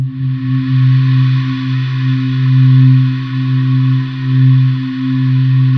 Index of /90_sSampleCDs/USB Soundscan vol.28 - Choir Acoustic & Synth [AKAI] 1CD/Partition C/07-DEEEP